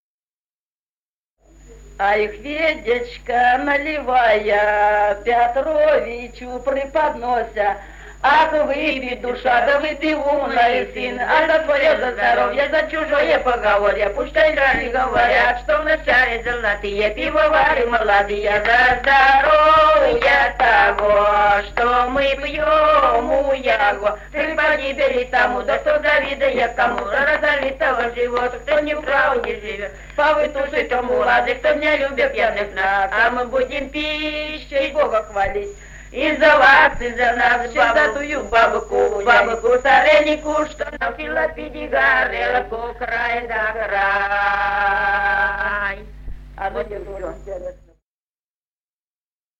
Народные песни Стародубского района «А и Хведечка наливая», застольная «банкетная» песня.
1959 г., с. Остроглядово.